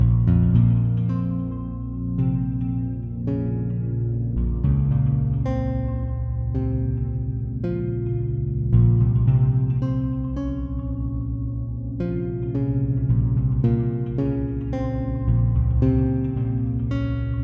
气氛吉他
描述：在佛罗里达州的工作室制作
Tag: 110 bpm Trap Loops Guitar Electric Loops 2.94 MB wav Key : A FL Studio